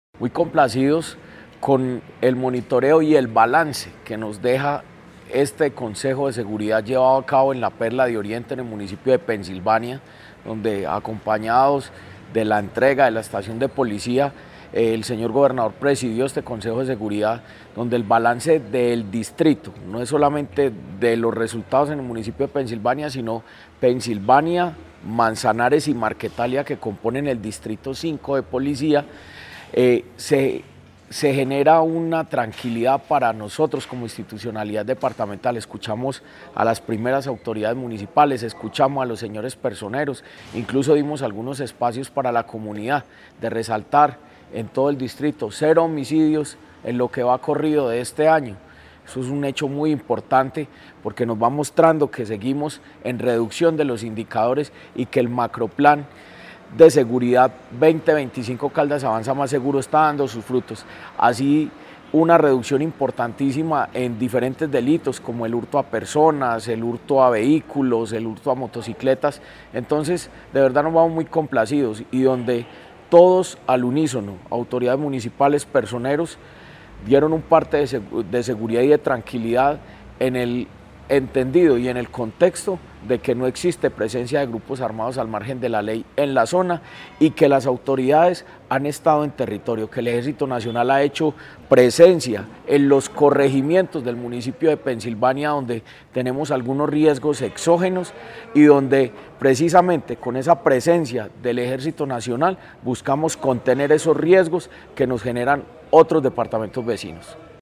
Jorge Andrés Gómez Escudero, secretario de Gobierno de Caldas.
Jorge-Andres-Gomez-Escudero-secretario-de-Gobierno-de-Caldas-Consejo-seguridad-Pensilvania.mp3